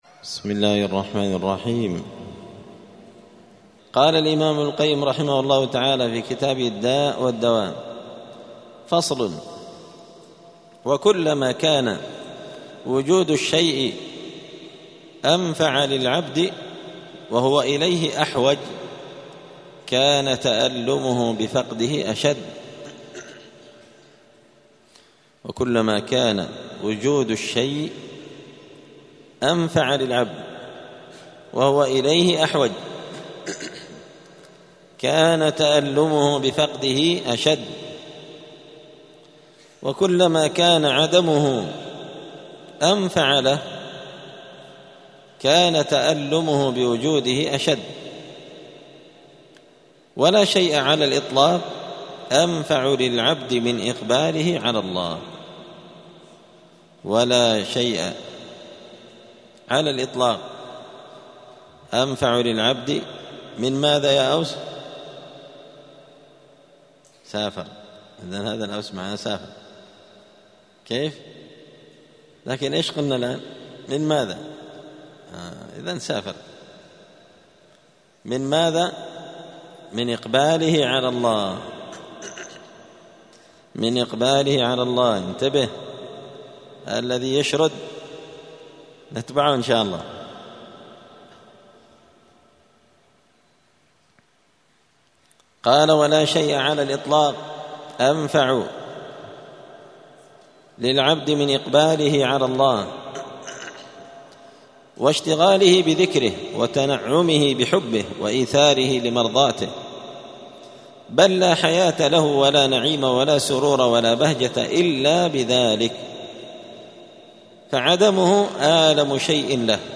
*الدرس الثالث والثمانون (83) فصل: الحب أصل كل عمل من حق وباطل*